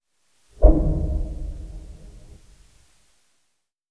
gong.wav